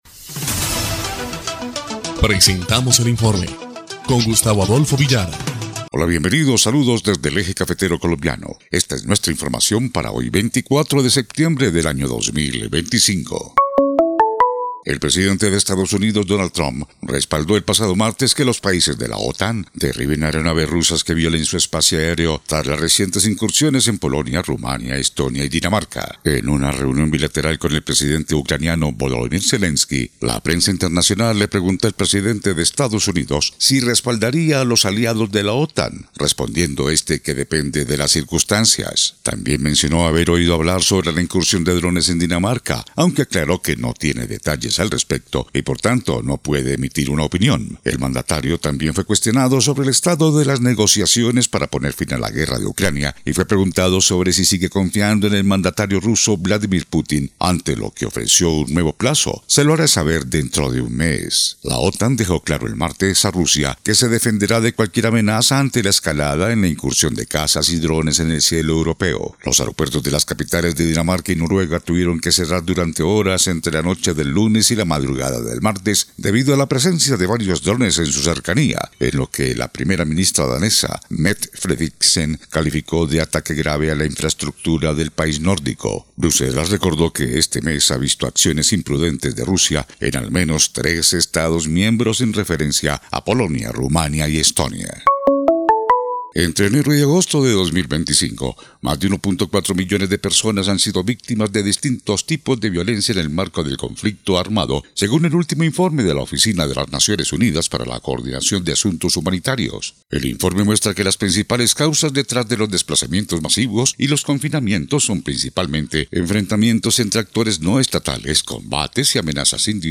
EL INFORME 1° Clip de Noticias del 24 de septiembre de 2025